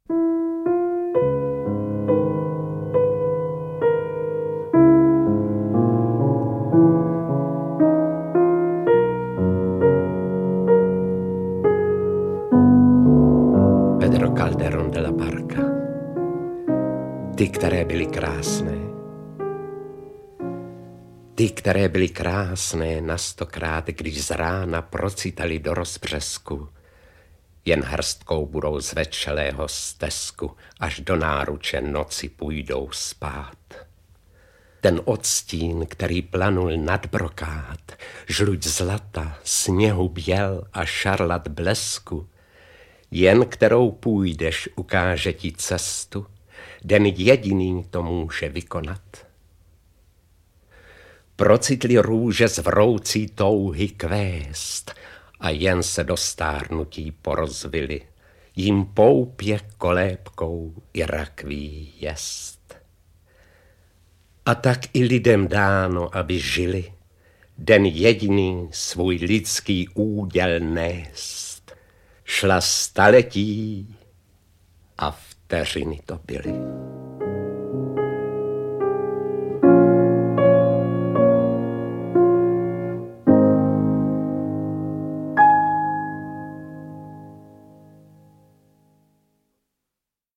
Zrcadlení audiokniha
Zrcadlení - audiokniha obsahuje sbírku poezie různých autorů a interpretů
Ukázka z knihy
• InterpretEduard Cupák, Jiří Adamíra, Irena Kačírková, Jan Přeučil, Luděk Munzar, Nina Divíšková, Hana Kofránková, František Vicena, Vladimír Šmeral, Ladislav Boháč